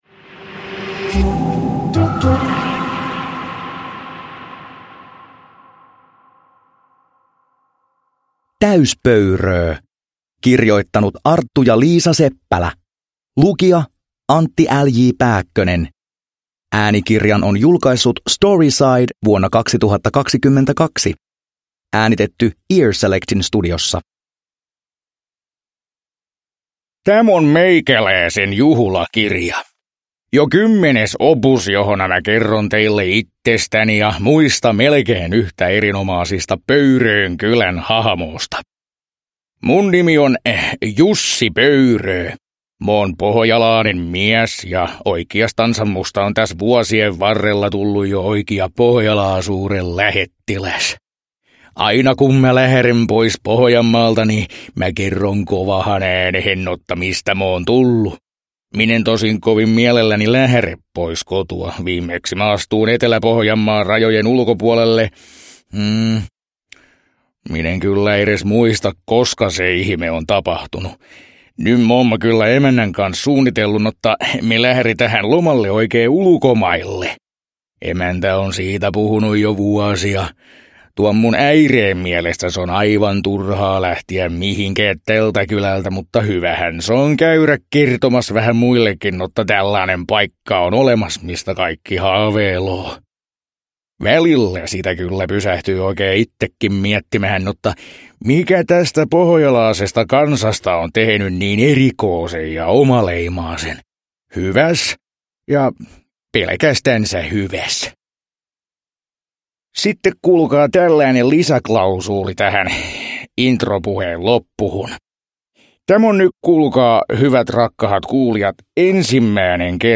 Täys Pöyröö – Ljudbok – Laddas ner
Uppläsare: Antti Pääkkönen